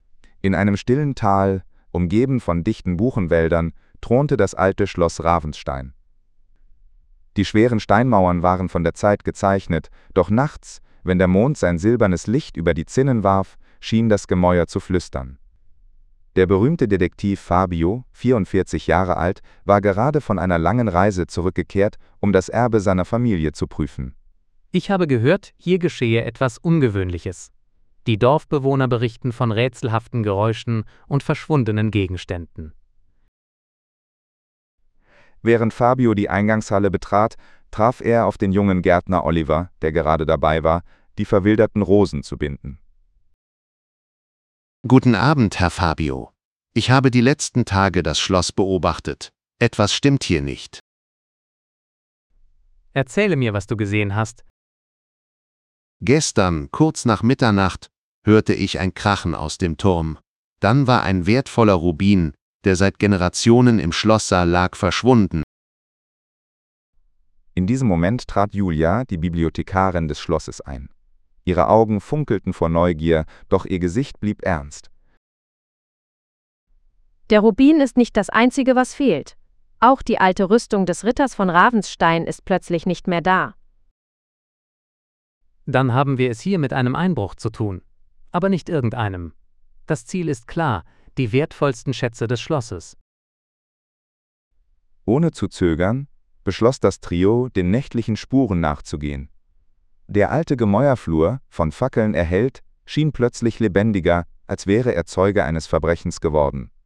🎧 Sample-Hörbuch (MP3)